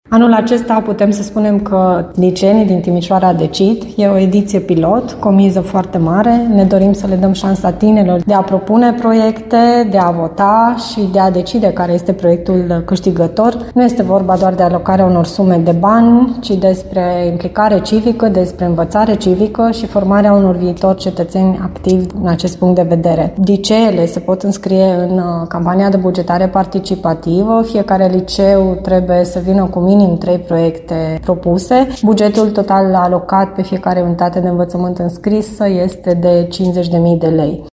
Inițiativa își dorește să stimuleze implicarea civică și formarea viitorilor cetățeni activi ai Timișoarei, spune viceprimarul Paula Romocean.